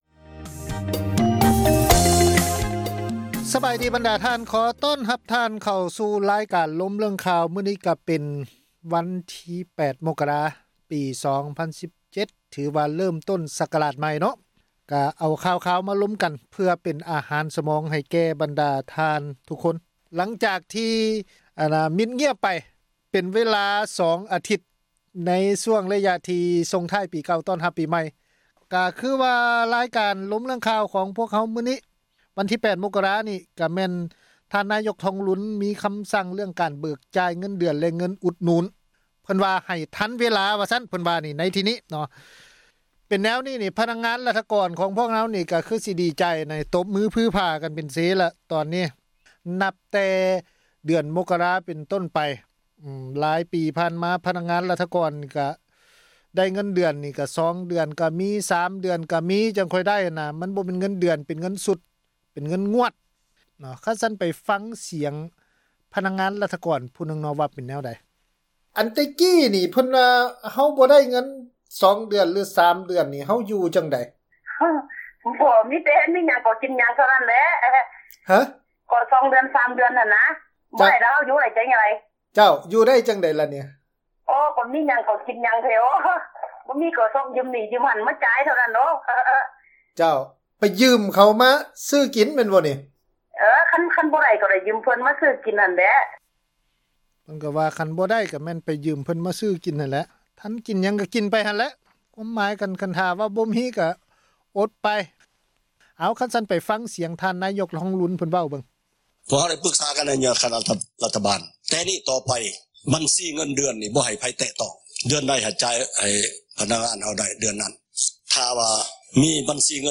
F-talk-show2 ການສົນທະນາ ເຖິງເຫດການ ທີ່ເກີດຂຶ້ນ ໃນແຕ່ລະມື້ ທີ່ມີຜົນກະທົບ ຕໍ່ຊີວິດປະຈໍາວັນ ຂອງຊາວລາວ ທົ່ວປະເທດ ທີ່ ປະຊາສັງຄົມ ເຫັນວ່າ ຂາດຄວາມເປັນທັມ.
ຣາຍການ ລົມເລື້ອງຂ່າວ ໃນ ວັນທີ 8 ມົກກະຣາ ໂດຍຫຍໍ້ ຊຶ່ງ ທ່ານຜູ້ຟັງ ຢູ່ ລາວ ໂທຣະສັບ ເຂົ້າມາ ອອກຄໍາເຫັນ ເຣື້ອງ ທ່ານ ທອງລຸນ ສັ່ງໃຫ້ເບີກ ເງິນເດືອນ ແລະ ເງິນ ອຸດໜູນ ໃຫ້ ທັນເວລາ.